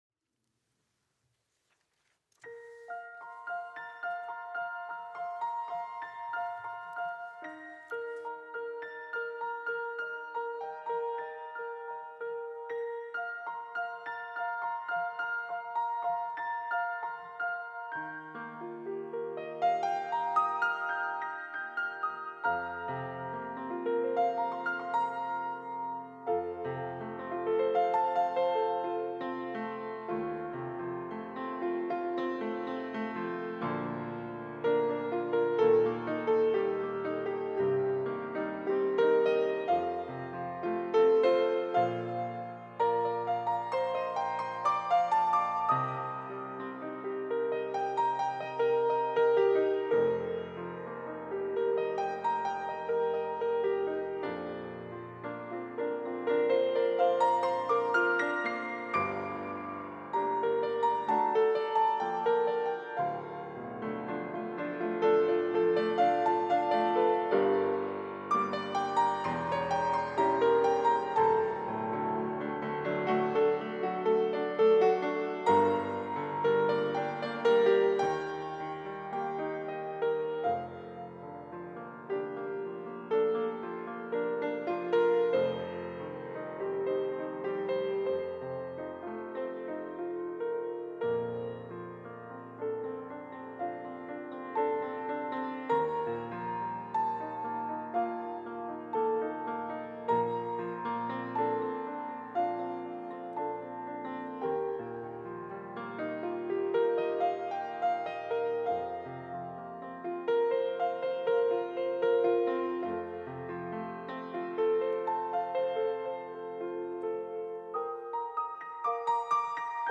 특송과 특주 - 주기도문